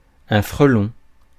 Ääntäminen
Synonyymit cul-jaune guichard lombarde talène cabridan Ääntäminen France: IPA: [fʁǝ.lɔ̃] Tuntematon aksentti: IPA: /fʁəlɔ̃/ Haettu sana löytyi näillä lähdekielillä: ranska Käännös 1. стъ̀ршел {m} (stǎ̀ršel) Suku: m .